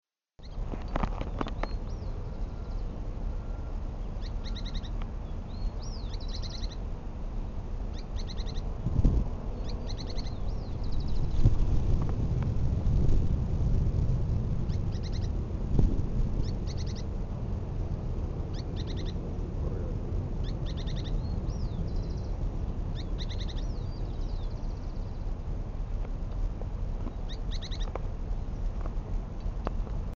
Pijuí Plomizo (Synallaxis spixi)
Lo pude escuchar muy cerca, muy enramado, cerca del final del camino del canal viamonte, del lado opuesto a éste.
Nombre en inglés: Spix´s Spinetail
Localidad o área protegida: Reserva Ecológica Costanera Sur (RECS)
Certeza: Vocalización Grabada